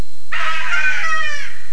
home *** CD-ROM | disk | FTP | other *** search / Horror Sensation / HORROR.iso / sounds / iff / sceam11.snd ( .mp3 ) < prev next > Amiga 8-bit Sampled Voice | 1992-09-02 | 35KB | 1 channel | 19,886 sample rate | 1 second
sceam11.mp3